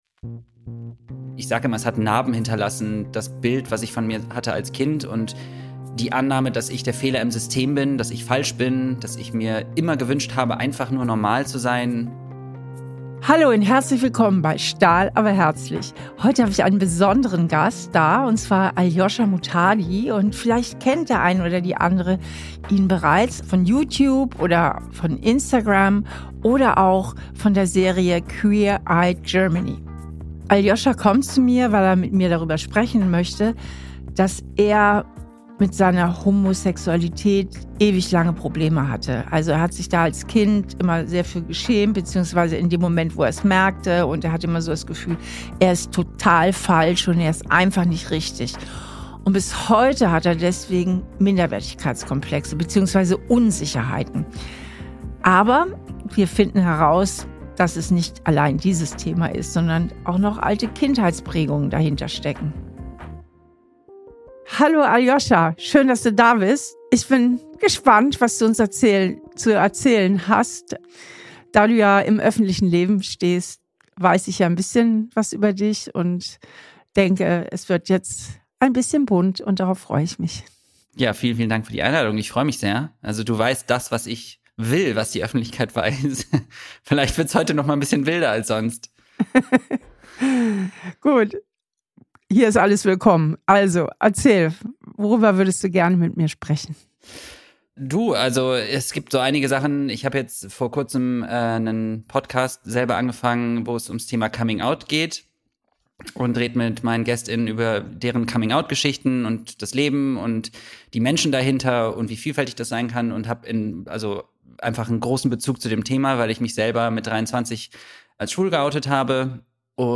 Aljosha Muttardi ist ein besonderer Gast in dieser Folge.